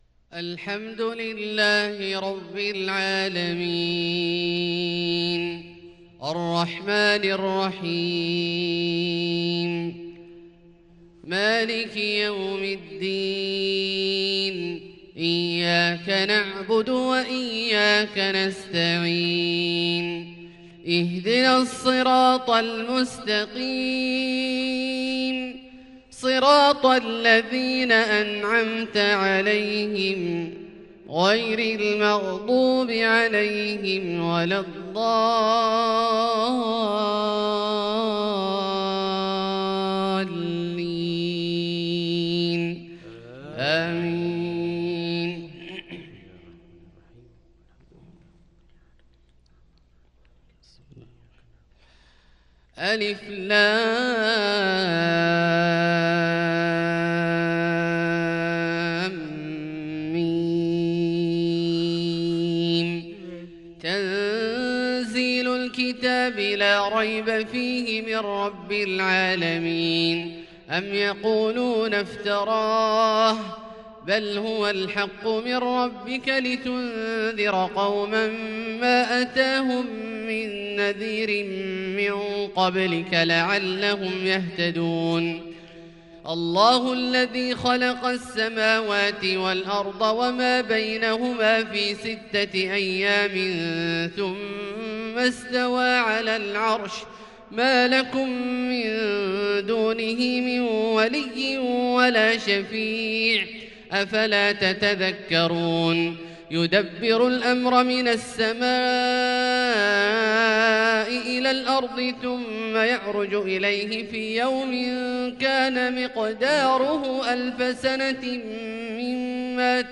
فجر الجمعة ٩-٦-١٤٤٢هـ سورتيّ السجدة والإنسان > ١٤٤٢ هـ > الفروض - تلاوات عبدالله الجهني